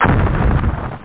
Amiga 8-bit Sampled Voice
BulletHit.mp3